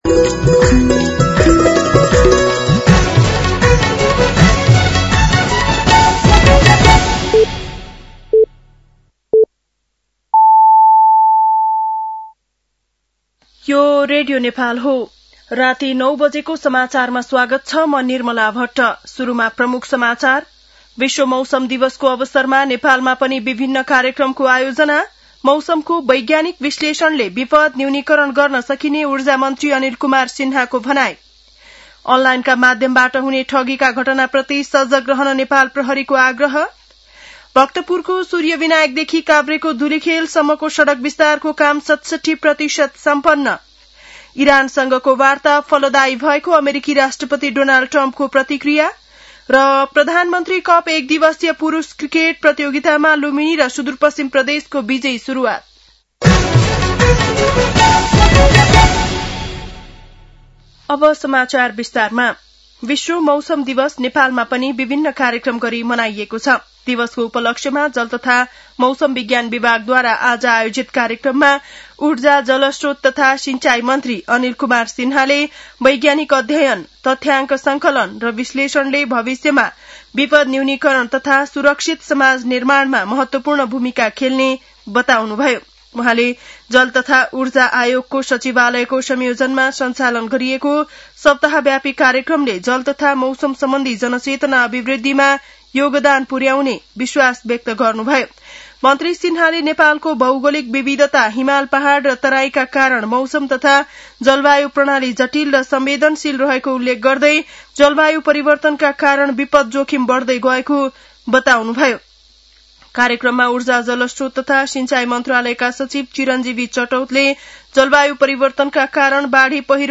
बेलुकी ९ बजेको नेपाली समाचार : ९ चैत , २०८२